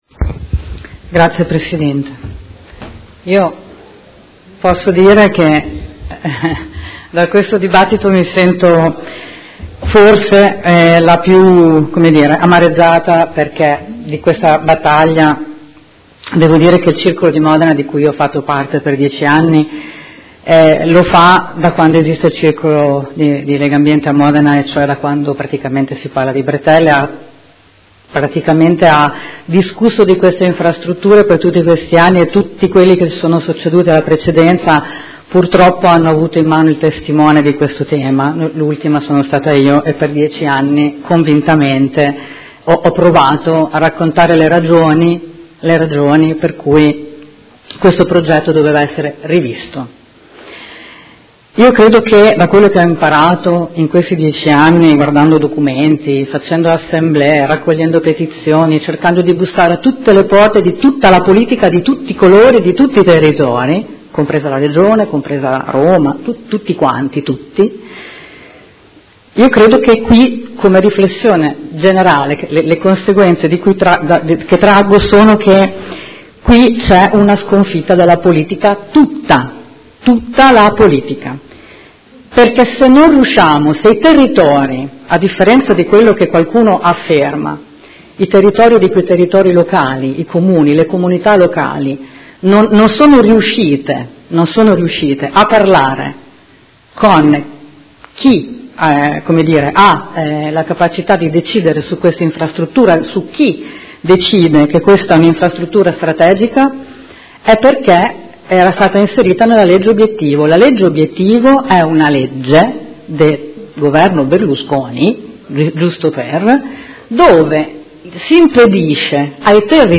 Seduta del 28/03/2019. Dibattito su Ordine del Giorno presentato dai Consiglieri Stella (SUM), dal Consigliere Chincarini (Modena Volta Pagina) e dal Consigliere Bussetti (M5S) avente per oggetto: Bretella autostradale Sassuolo- Campogalliano: opera inutile e dannosa per il nostro ambiente: finanziare la manutenzione della viabilità esistente e sviluppare il potenziamento e la qualificazione delle tratte ferroviarie Sassuolo- Modena e Sassuolo-Reggio, ed emendamento; Ordine del Giorno Prot. Gen. n. 93778; Ordine del Giorno Prot. Ge. n. 93779